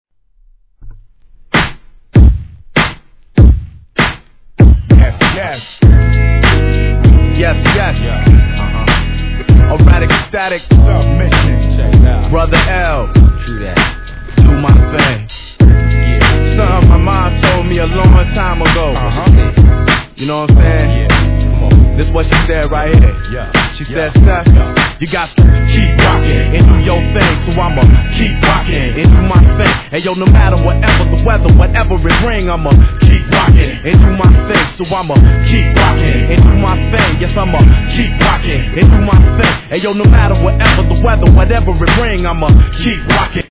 1. HIP HOP/R&B
タイトなビートに浮遊感ある気持ちのいい上音が重なるトラックに切れ良くリリックを乗せたシカゴスタイルでキメッ！！